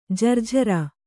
♪ jarjhara